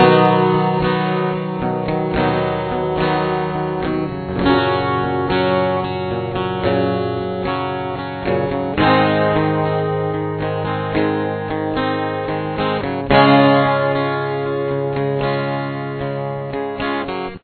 Here is the intro with just the chords :